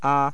4. Vowel Formants.
The vowels sound like this:
[a]